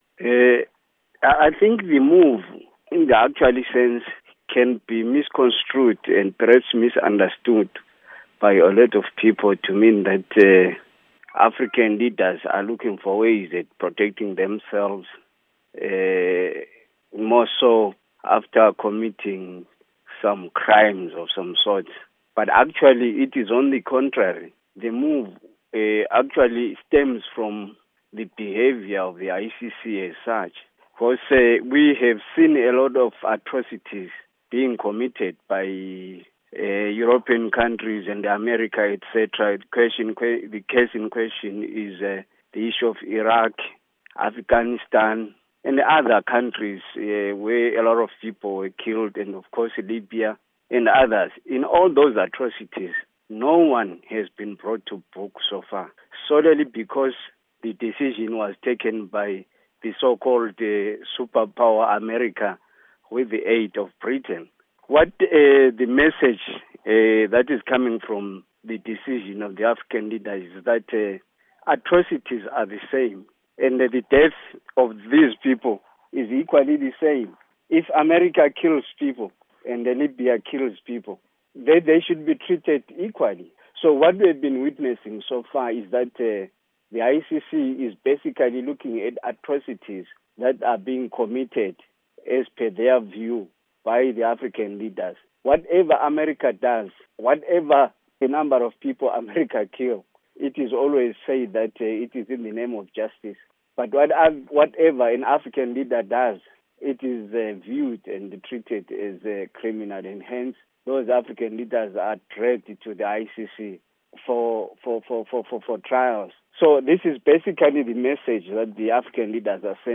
'ICC Puppet of Western Nations': Interview